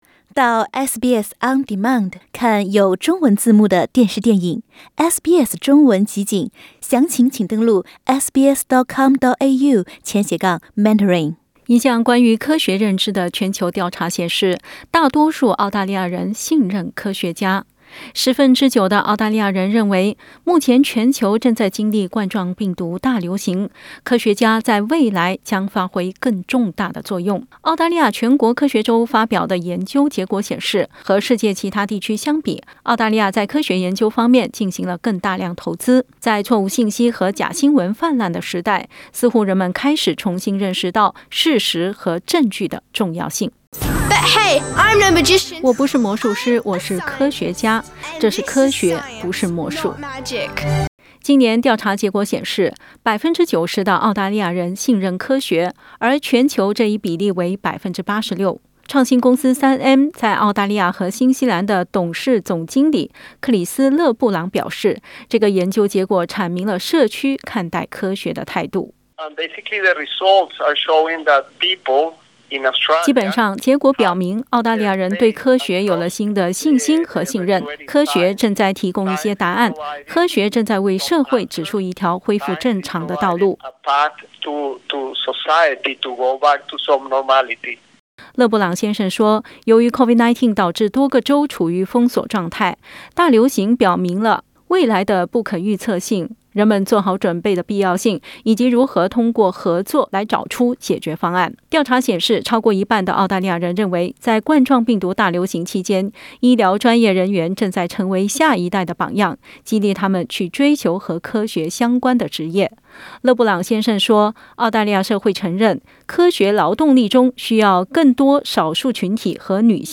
（点击图片收听详细报道）